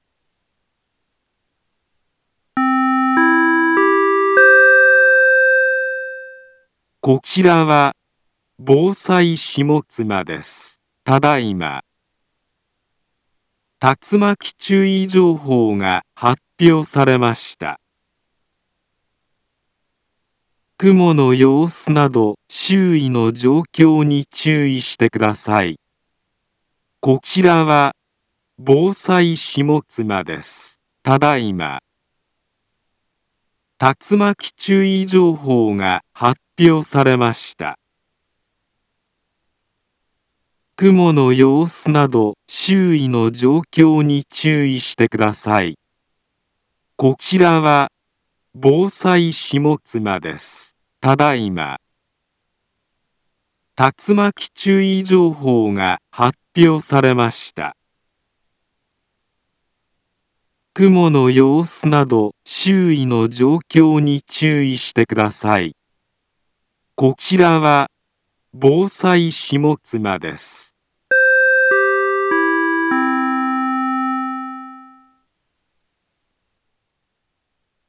Back Home Ｊアラート情報 音声放送 再生 災害情報 カテゴリ：J-ALERT 登録日時：2024-07-26 20:09:29 インフォメーション：茨城県北部、南部は、竜巻などの激しい突風が発生しやすい気象状況になっています。